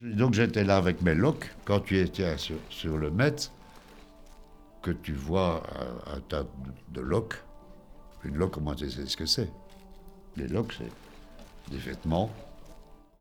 prononciation Loque ↘ explication Donc j'étais là avec mes loques, quand tu étais sur le met( = le marché), que tu vois un tas de loques.